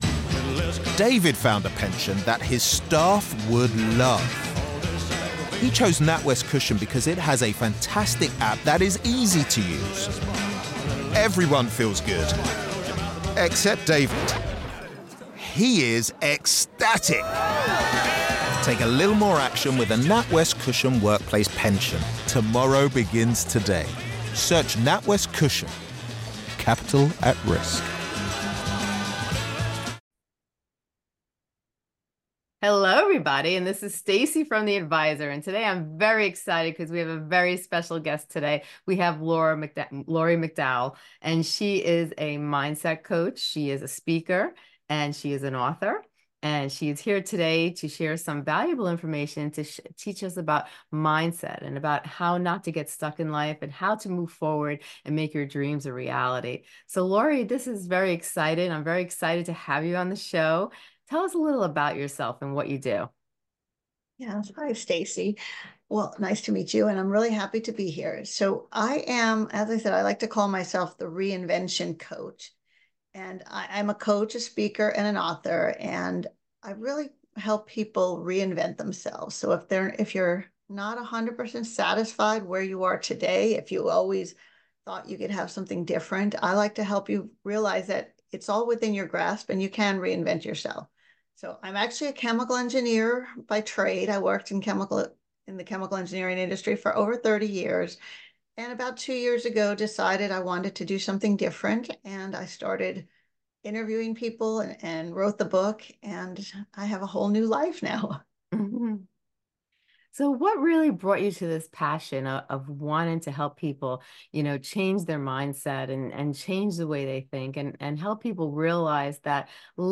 Don't miss out on this captivating conversation that will equip you with the tools you need to reimagine your future.